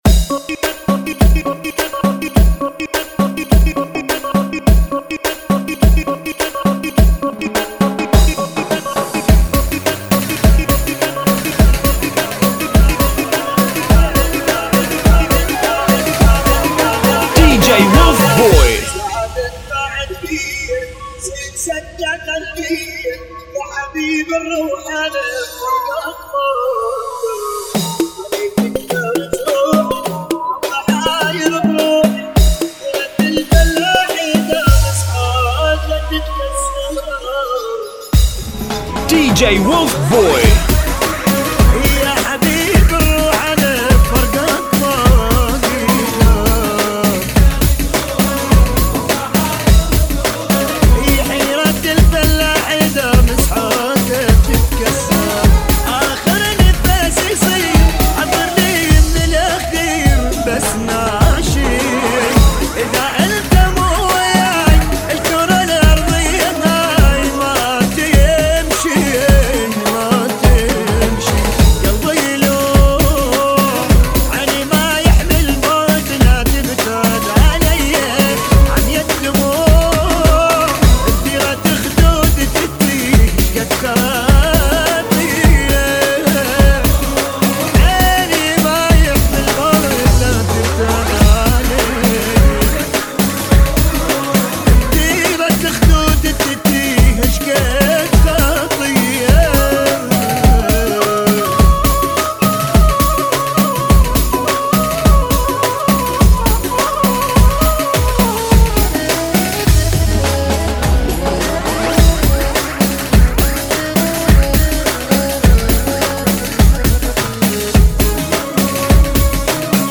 [ 104 Bpm ]